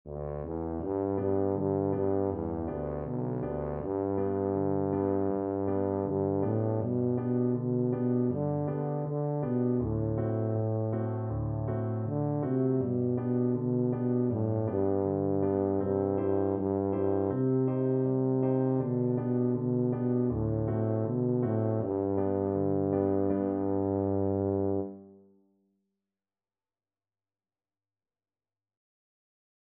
Tuba
B2-D4
G major (Sounding Pitch) (View more G major Music for Tuba )